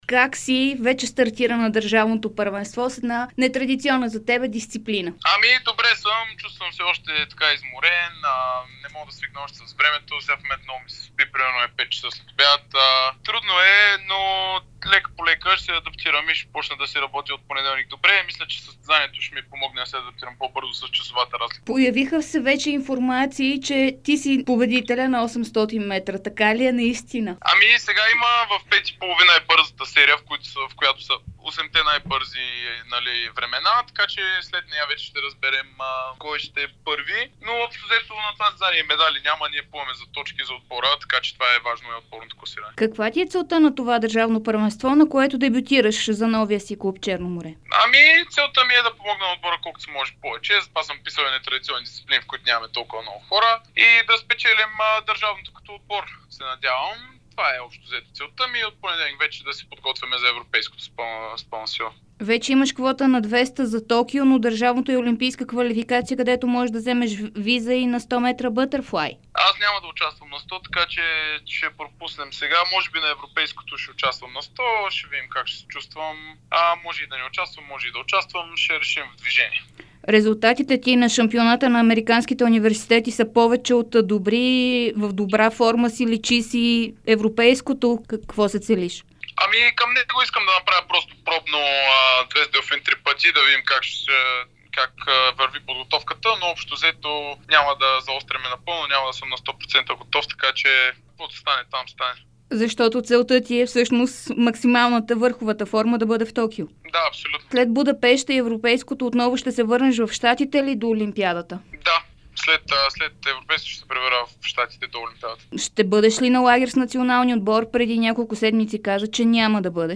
Най-добрият български плувец Антъни Иванов даде специално интервю за Дарик радио и dsport.